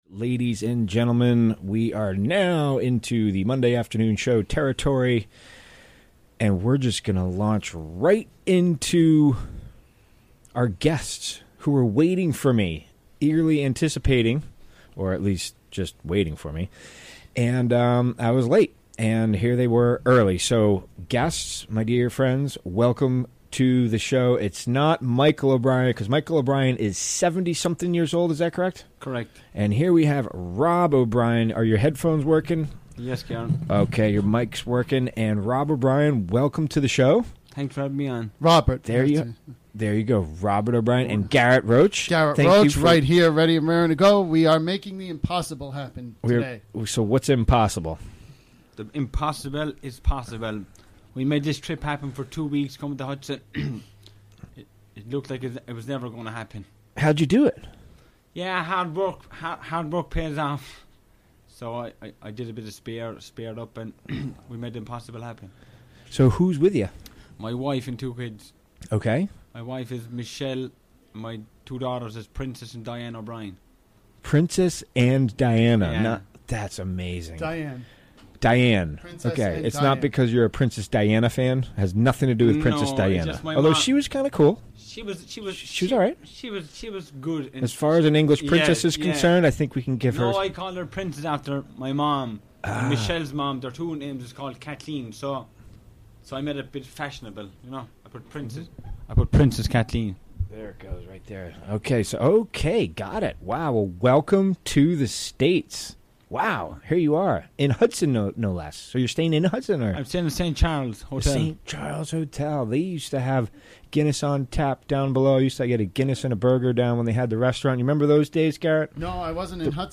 Interviewed
Recorded during the WGXC Afternoon Show Monday, December 11, 2017.